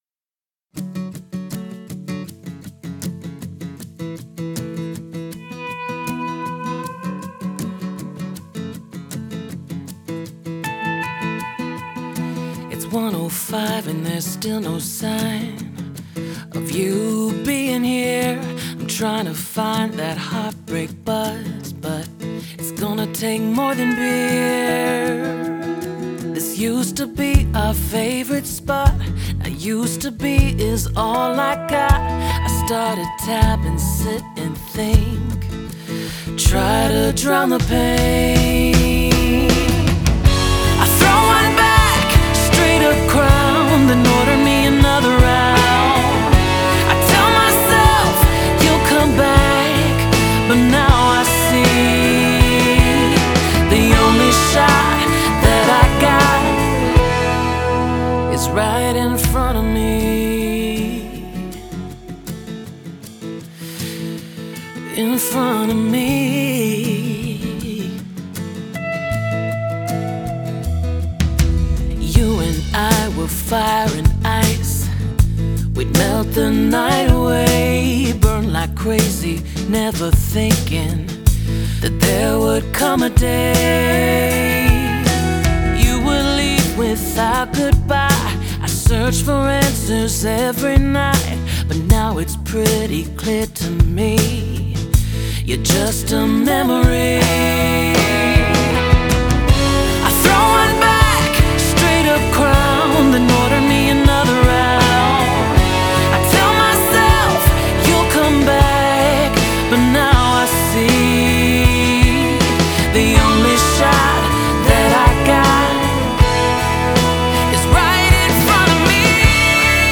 country song